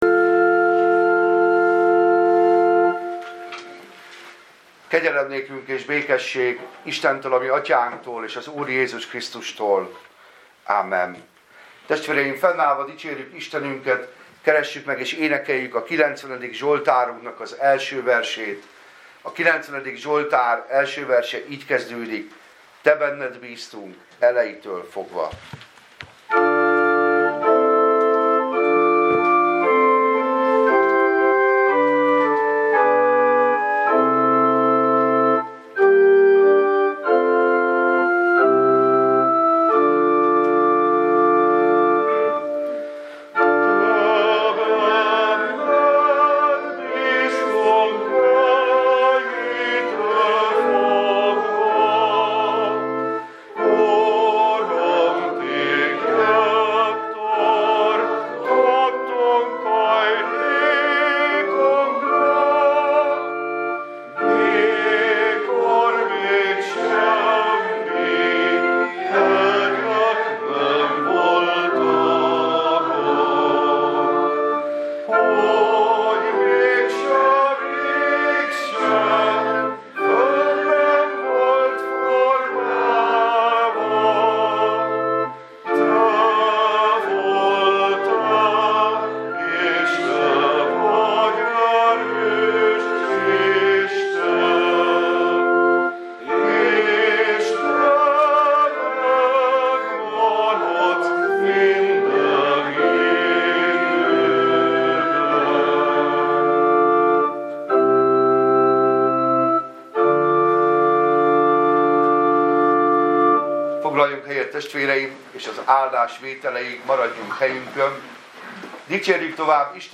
Passage: Jn 10, 11-15 Service Type: Igehirdetés